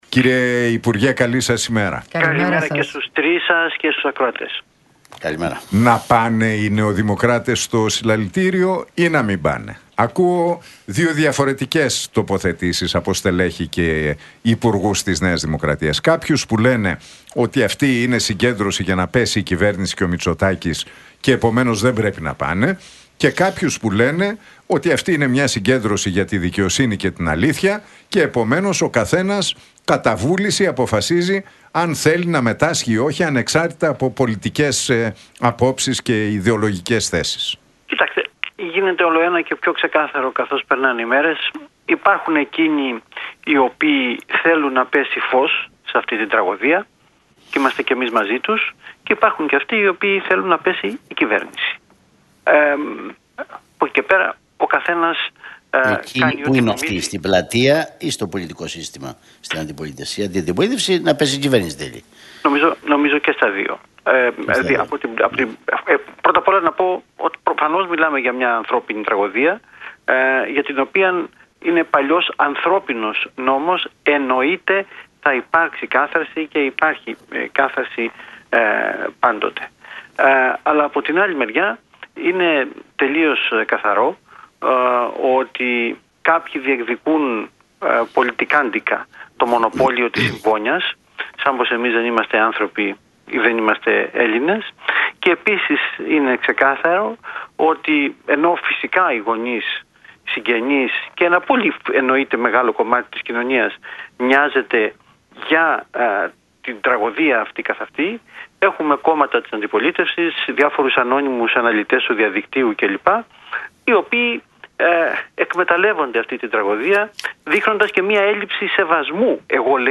μίλησε ο υπουργός Εθνικής Οικονομίας και Οικονομικών, Κωστής Χατζηδάκης
από την συχνότητα του Realfm 97,8